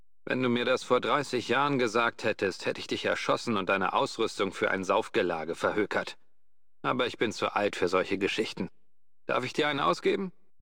Fallout: Brotherhood of Steel: Audiodialoge
FOBOS-Dialog-Fremder_im_Ödland-003.ogg